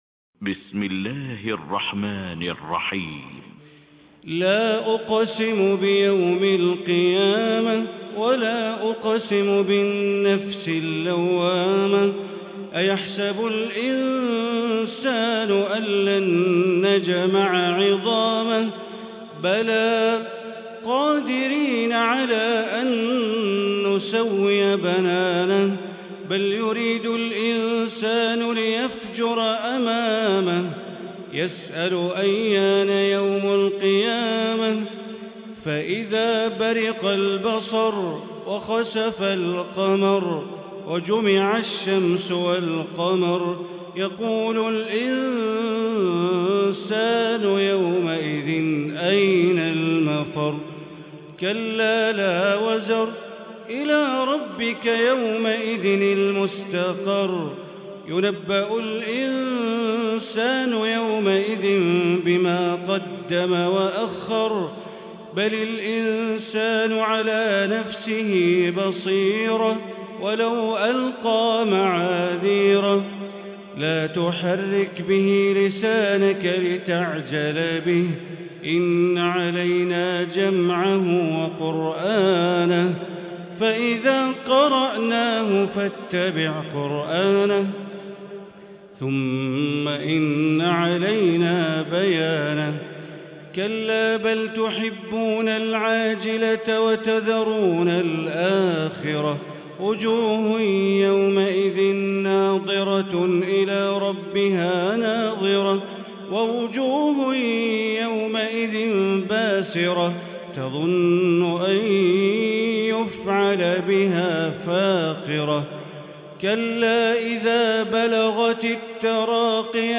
Tarawih prayer from the holy Mosque